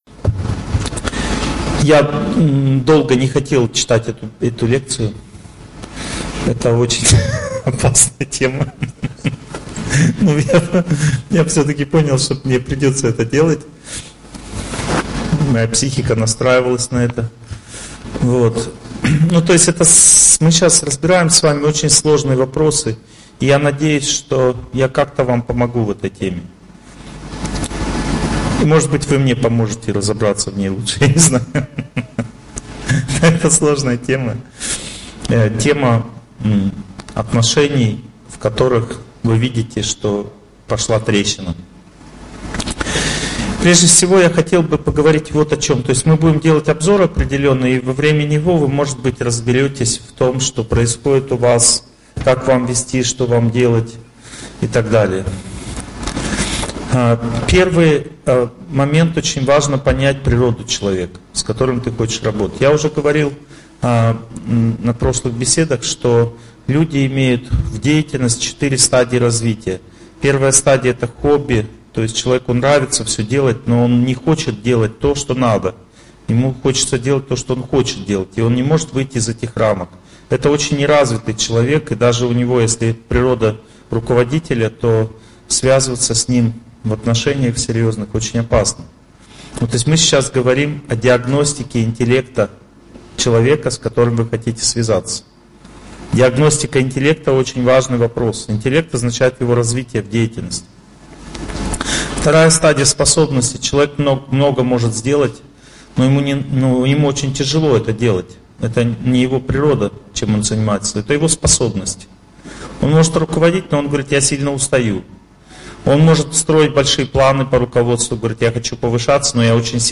Аудиокнига Вынужденные, деловые, партнерские, близкие отношения в управлении и бизнесе. В чем разница и каковы последствия | Библиотека аудиокниг